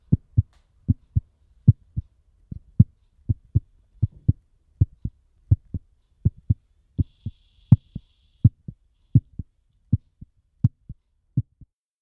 heartbeat.mp3